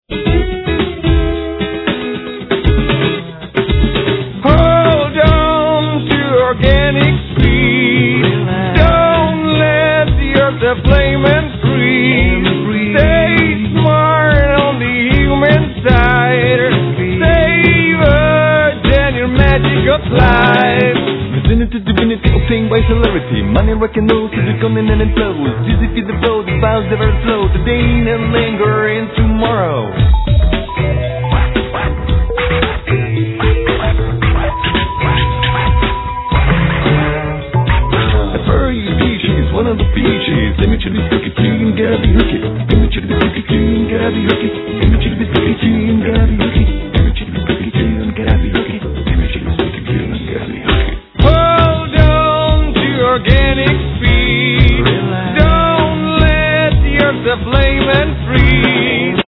El. & Ac. guitar, Back vocals.
El. guitar, Ac. & El. Violin
Bass, Double bass, Hammonds, Yamaha DX7, Percussions, Noise